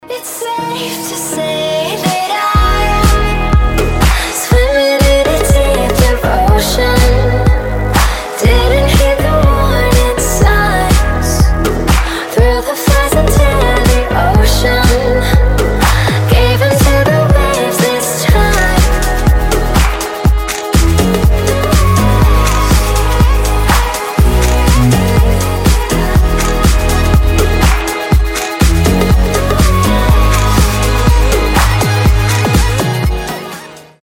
поп
женский вокал
dance
романтические